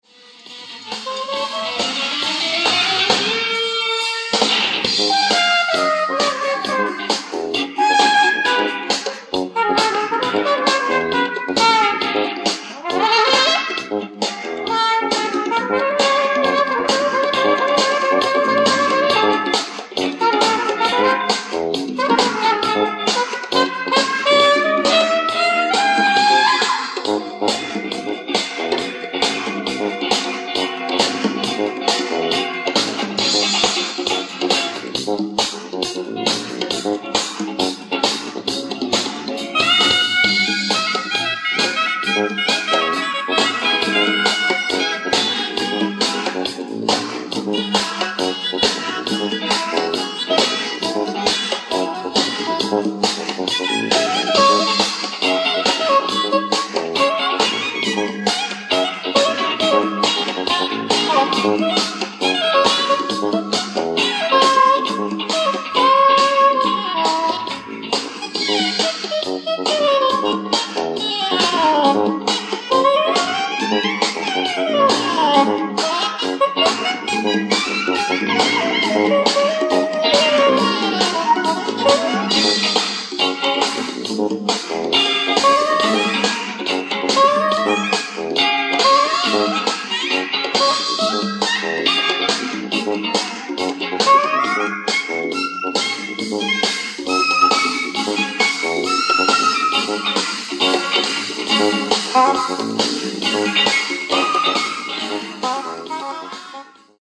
Live At Ford Auditorium, Detroit, MI
GOOD AUDIENCE RECORDING